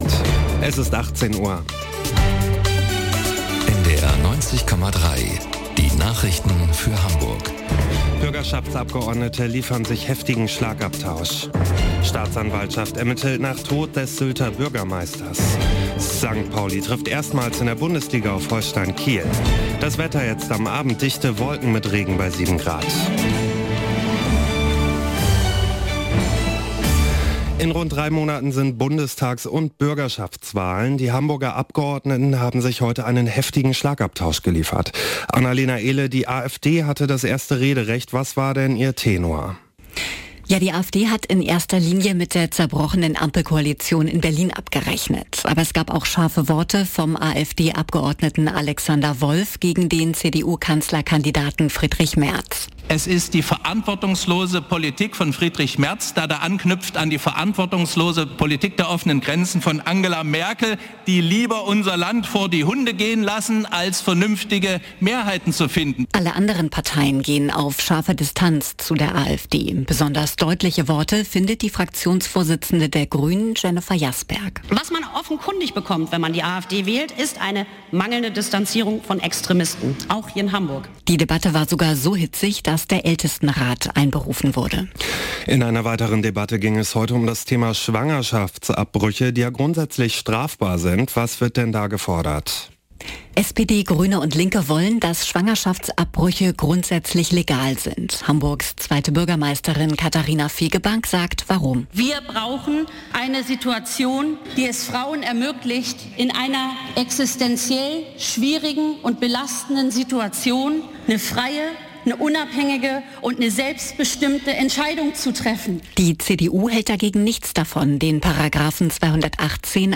1 Nachrichten 6:28